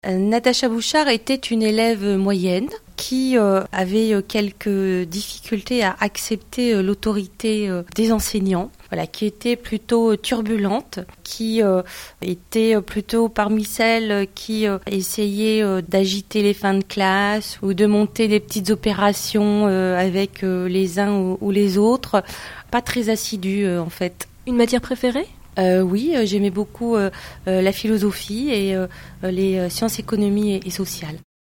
A cette occasion, Radio 6 est allée à la rencontre des maires du Calaisis pour savoir comment ils vivaient leur rentrée lorsqu’ils étaient enfants. Et pour ce premier numéro de la semaine, nous avons tendu le micro à la maire de Calais, Natacha Bouchart.